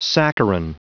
Prononciation du mot saccharin en anglais (fichier audio)
Prononciation du mot : saccharin